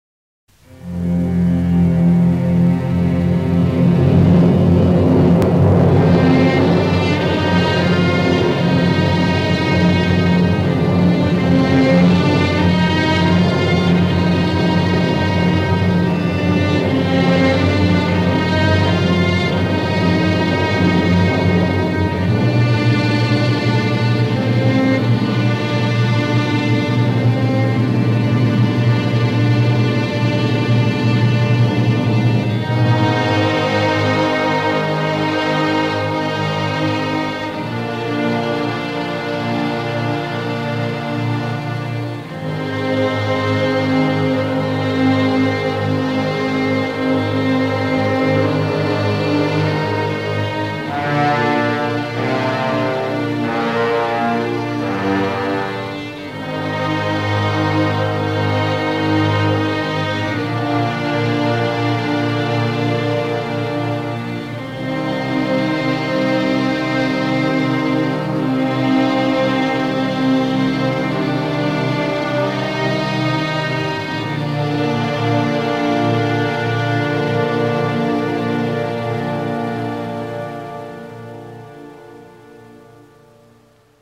Otras películas donde se toca el ARPA